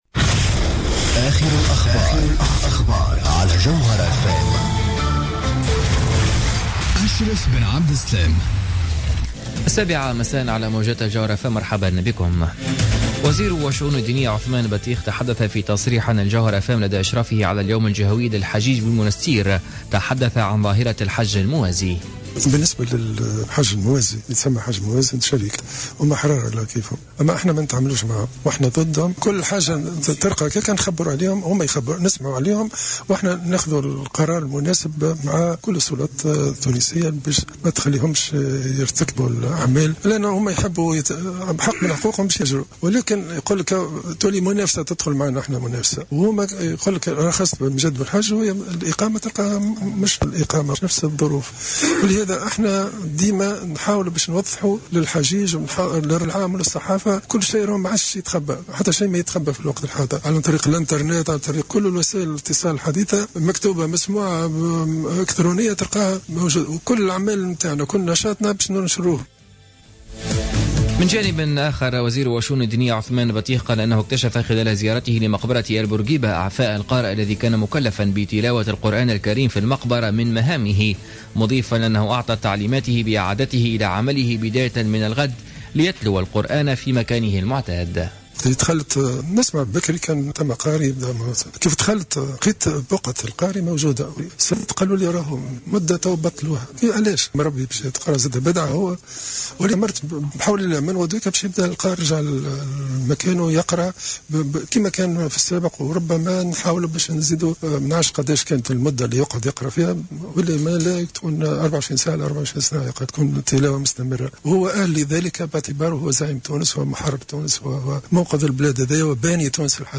نشرة أخبار السابعة مساء ليوم الخميس 27 أوت 2015